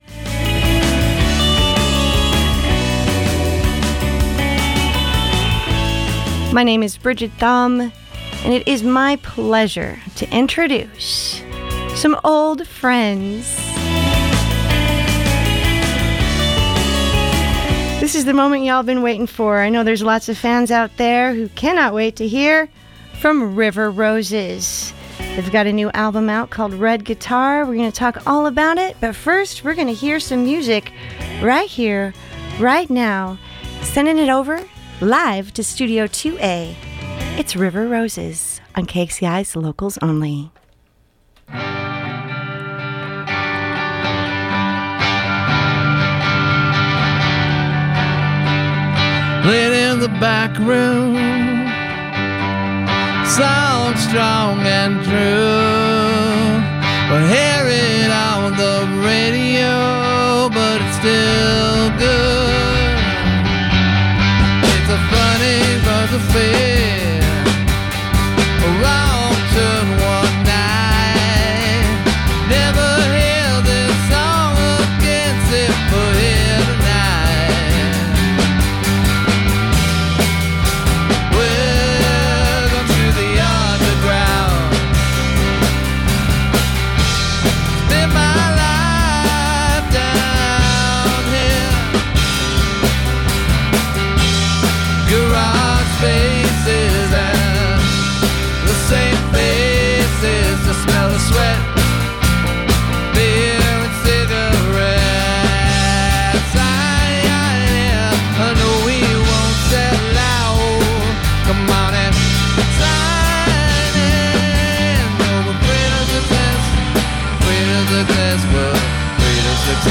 Listen to the live performance + interview here!
Southwestern americana band
TAGS country , desert rock , indie folk rock